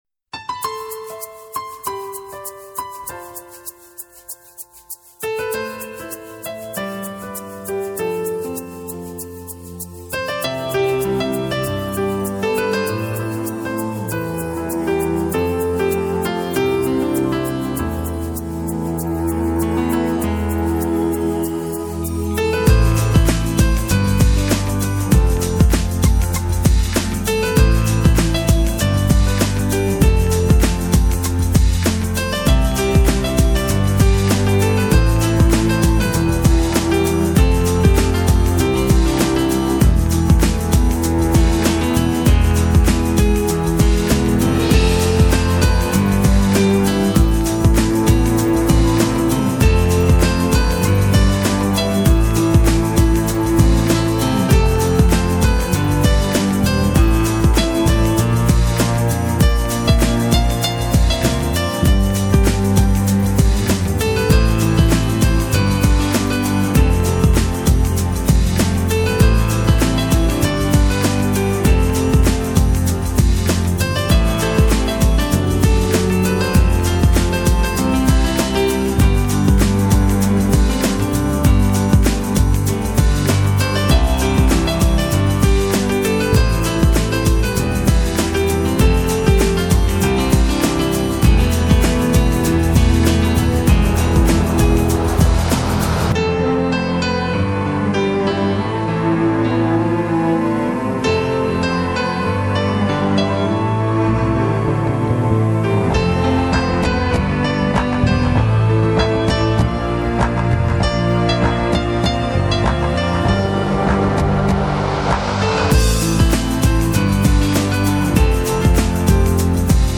【音频素材】背景音乐 - 轻快.mp3